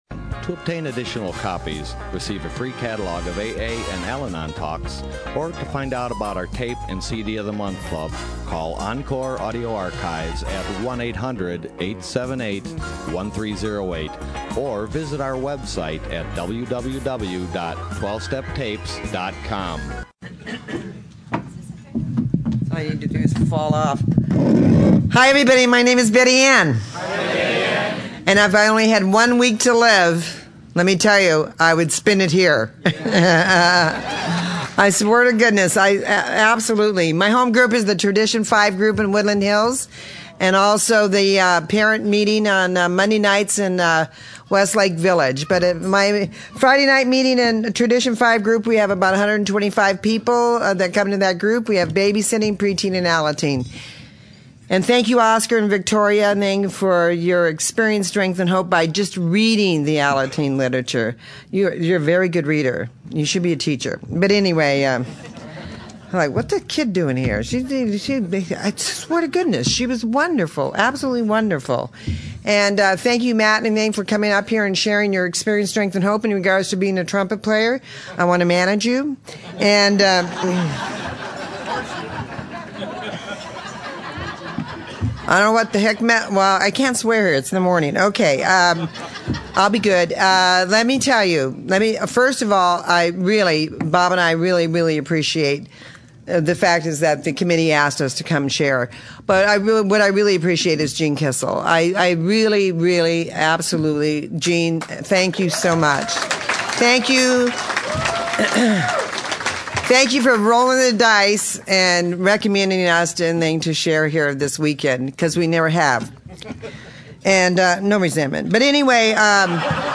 SOUTHBAY ROUNDUP 2010